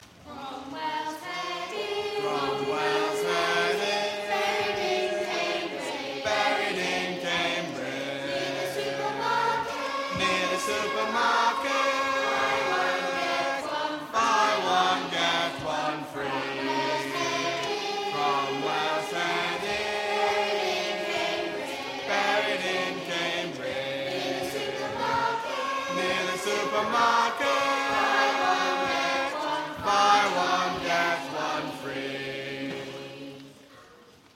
a round
Singing History Concert